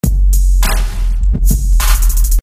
TR808环路3
描述：TR808，鼓组，低音炮
Tag: 102 bpm Hip Hop Loops Drum Loops 405.38 KB wav Key : Unknown